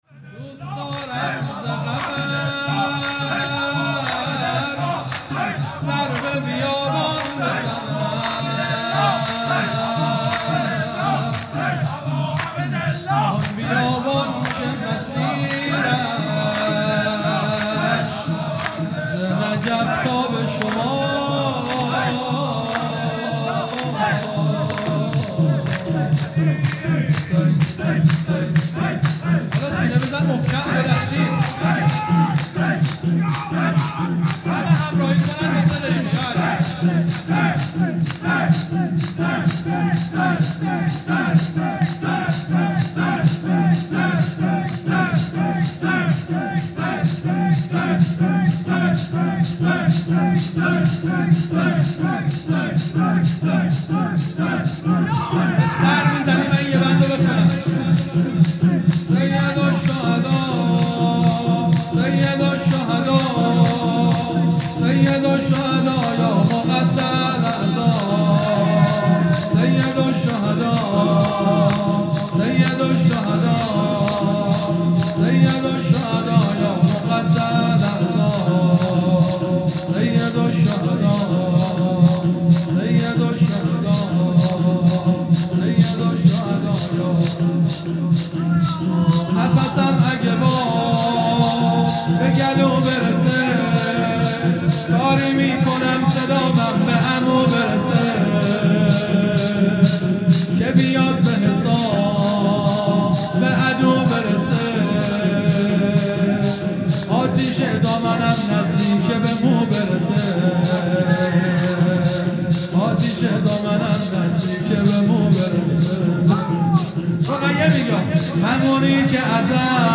شور
شهادت حضرت رقیه (س) | ۳ آبان ۱۳۹۶